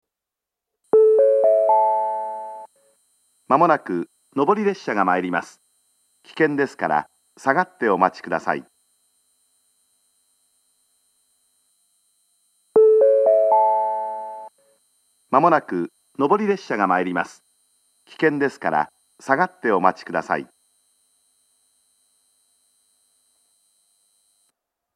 gosawa-nobori-sekkinn.mp3